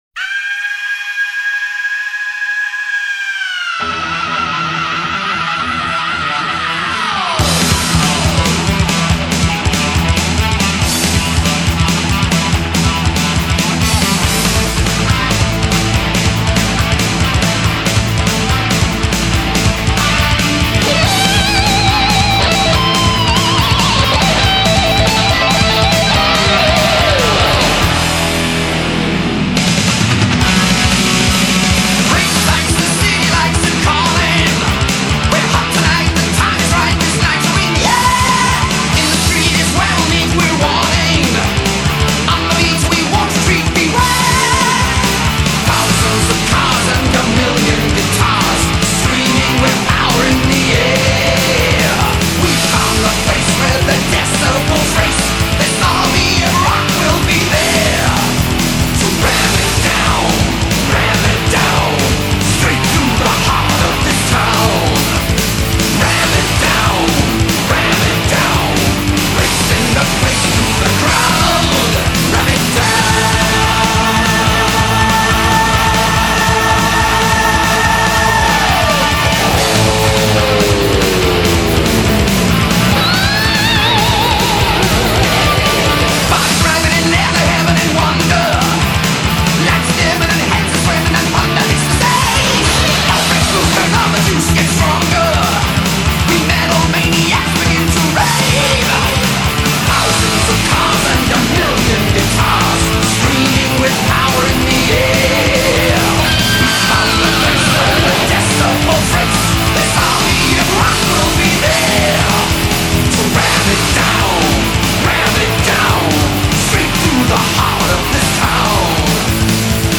آلبوم هوی متال
Heavy Metal